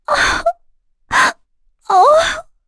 Erze-Vox_Sad_kr.wav